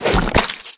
New attack sounds for mudcrawlers
mud-glob.ogg